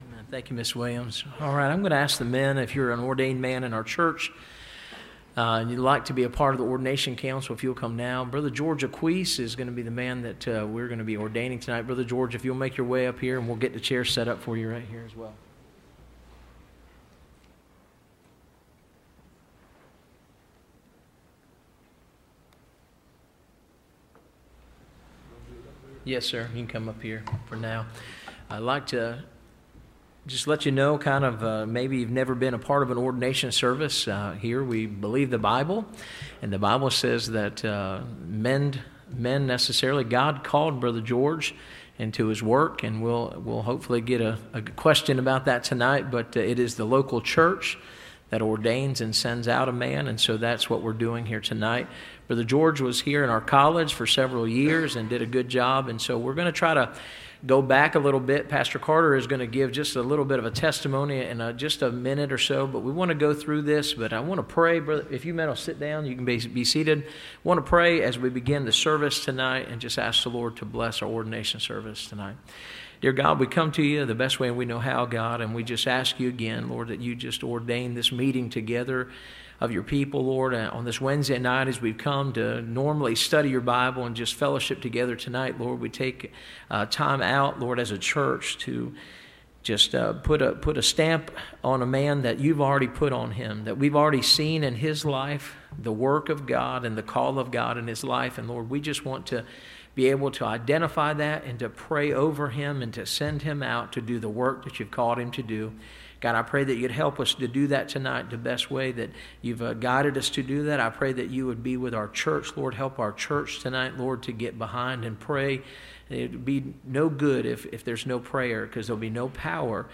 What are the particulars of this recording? Ordination Service – Landmark Baptist Church